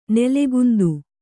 ♪ nelegundu